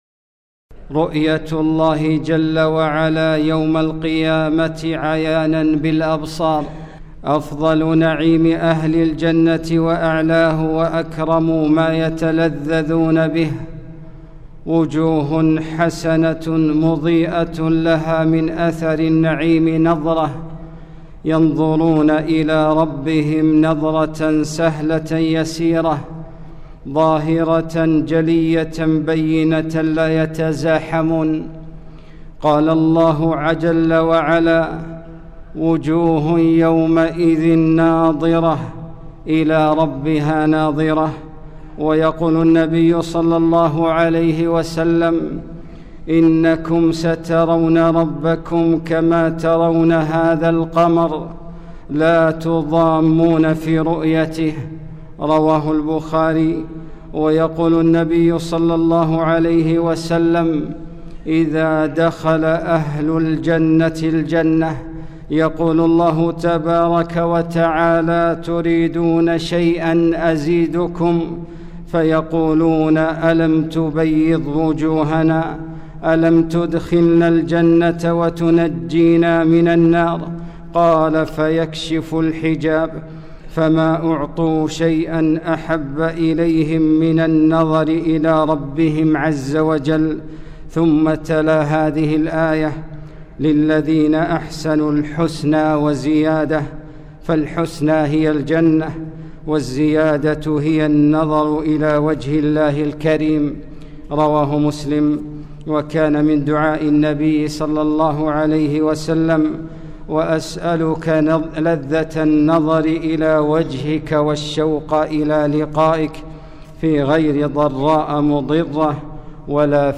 خطبة - رؤية الله جل وعلا